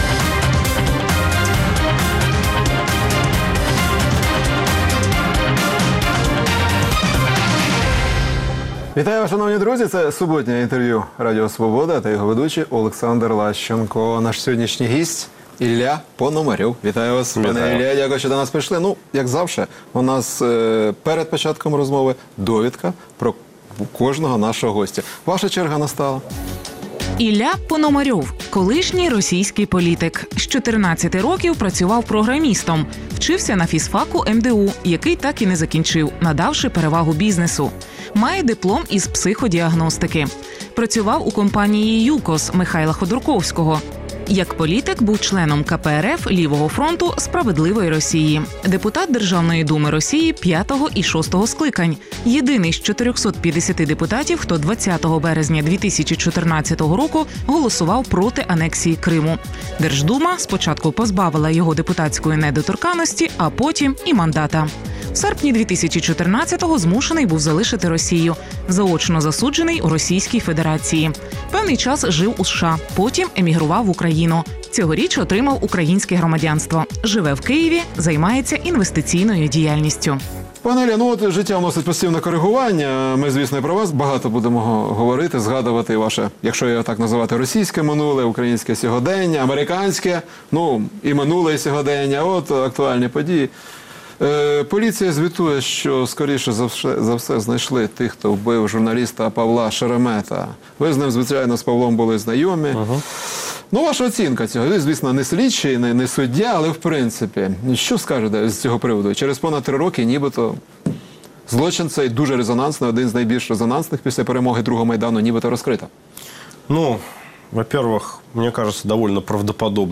Суботнє інтерв’ю | Ілля Пономарьов, екс-депутат Держдуми Росії
Суботнє інтвер’ю - розмова про актуальні проблеми тижня. Гість відповідає, в першу чергу, на запитання друзів Радіо Свобода у Фейсбуці